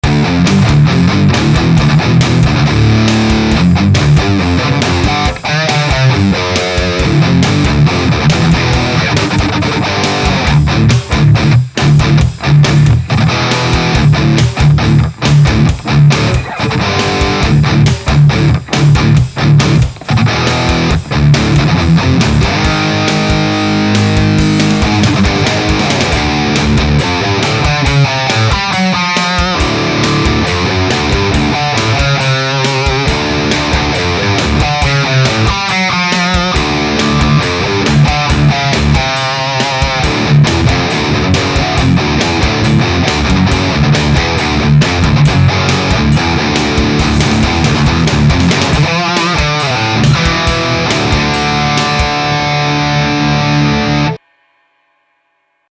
My feeling is that the RR is very much able to pull it off with the right setup and playing, as it's very tight and crunchy.
Edgy and hard rocking!
This was Axe FX, VH4 Model, through VHT 2502 and Bogner 4x12 through a single SM57. Volume was very low, I think I should crank that bugger up more for this kind of tone, but poor neighbours don't deserve that kind of treatment early morning.